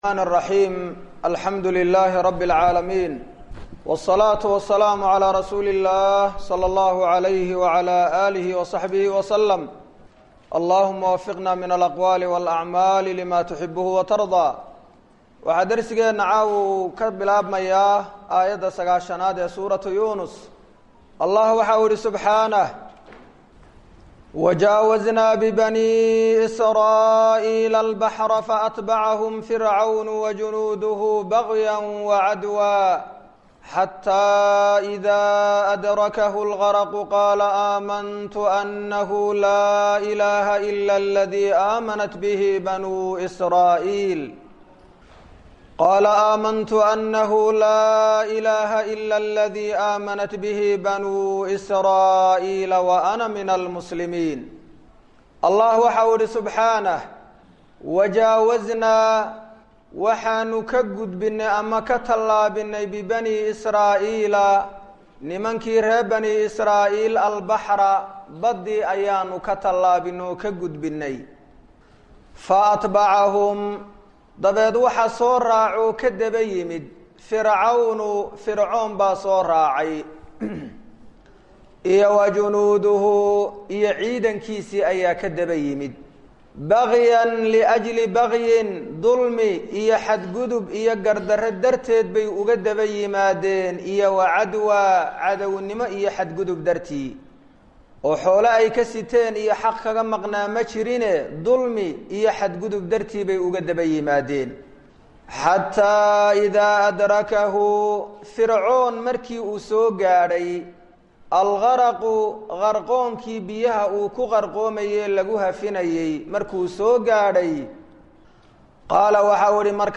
Waa Tafsiirka Qur’aanka Ee Ka Socda Masjid Ar-Rashiid – Hargaisa